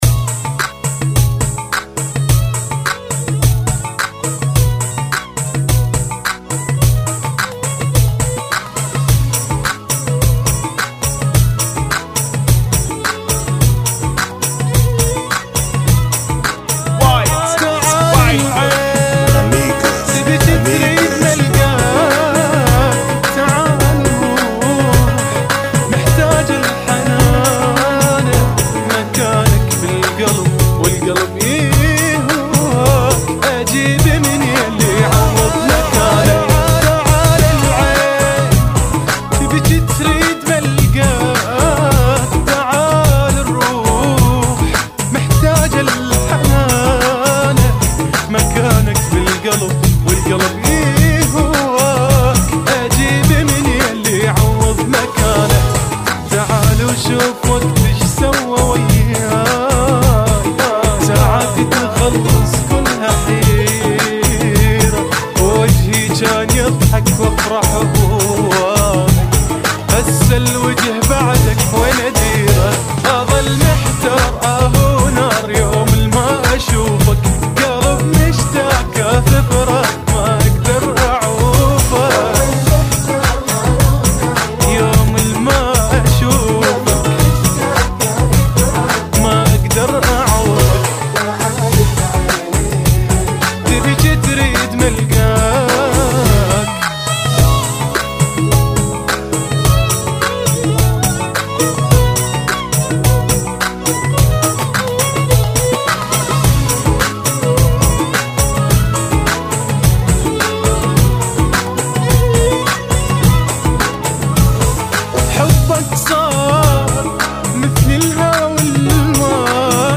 [ 106 Bpm ]